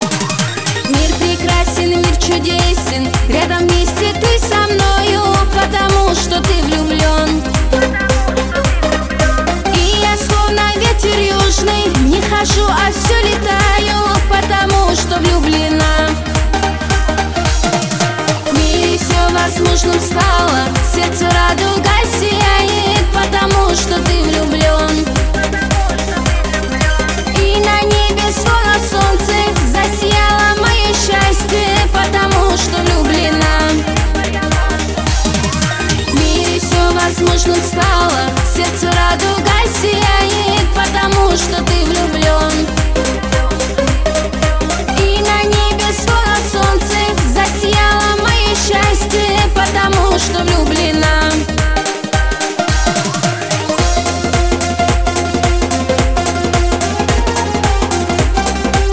• Качество: 320, Stereo
поп
ритмичные
восточные
кавказские